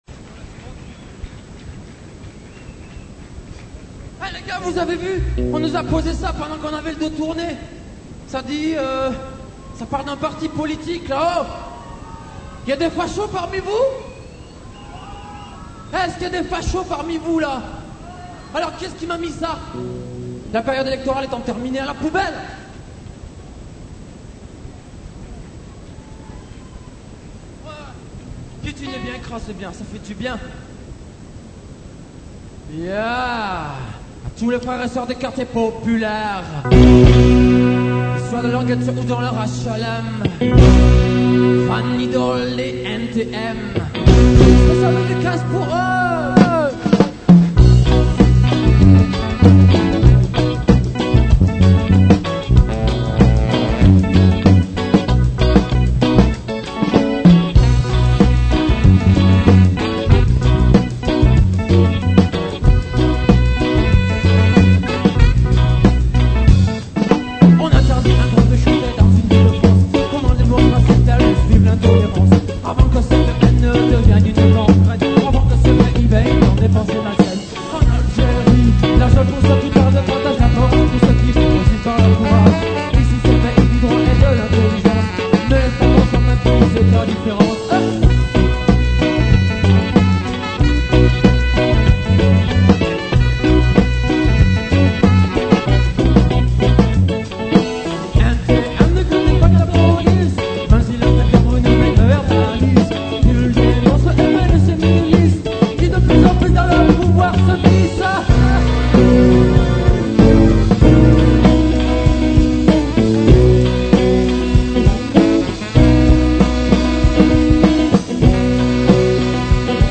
reggea